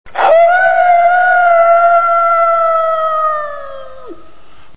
Scream***
(Der Schrei vom Serienanfang 0:04)
scream.mp3